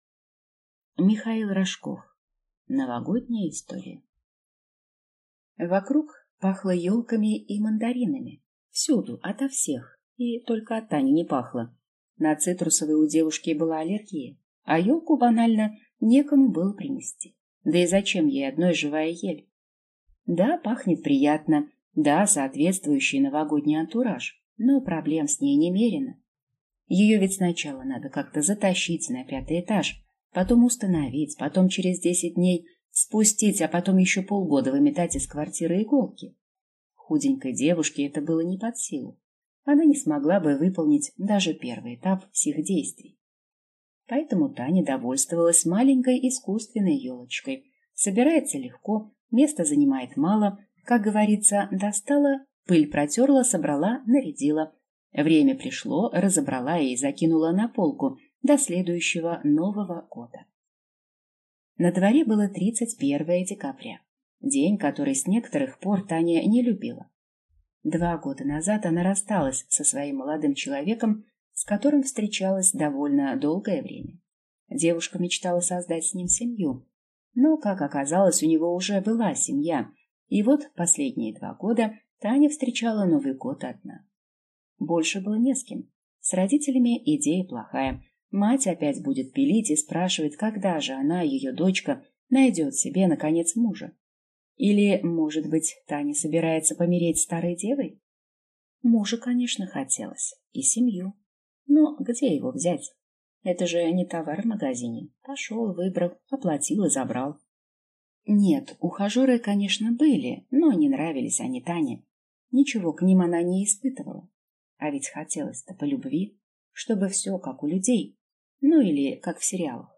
Аудиокнига Новогодняя история | Библиотека аудиокниг